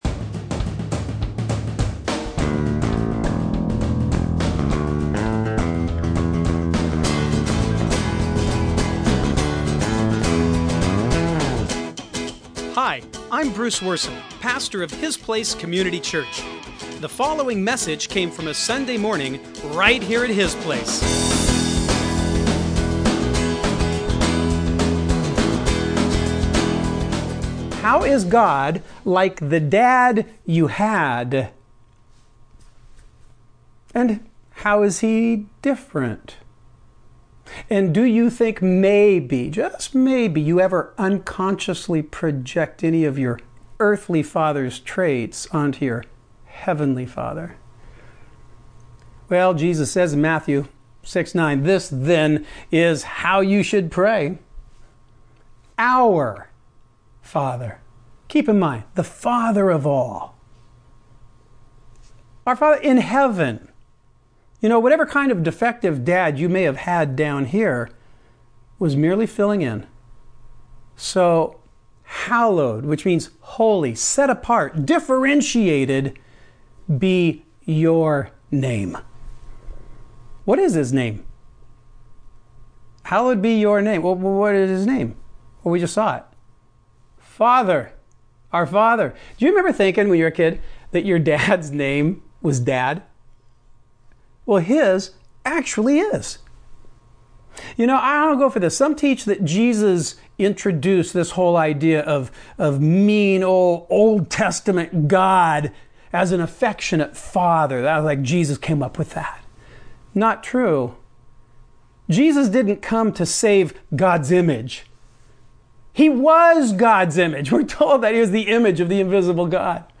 Sunday morning messages from His Place Community Church in Burlington, Washington. These surprisingly candid teachings incorporate a balanced mix of lighthearted self-awareness and thoughtful God-awareness.